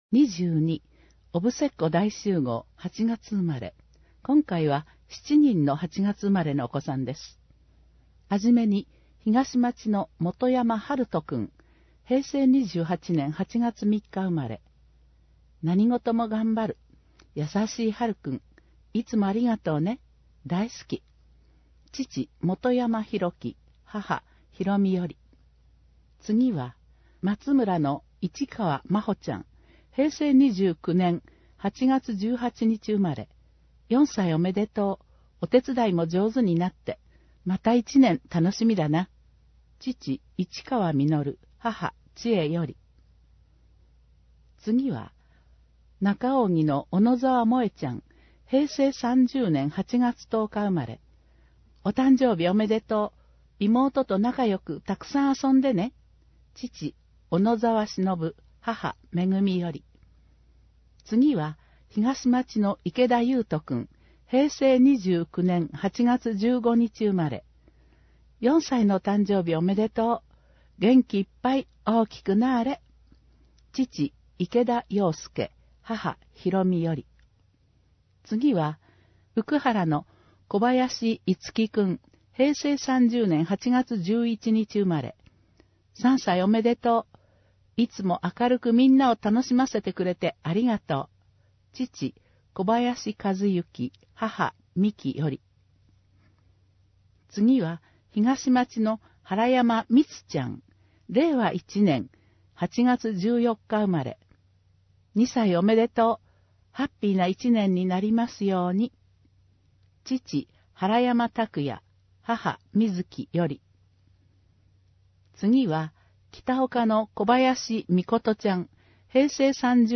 毎月発行している小布施町の広報紙「町報おぶせ」の記事を、音声でお伝えする（音訳）サービスを行っています。音訳は、ボランティアグループ そよ風の会の皆さんです。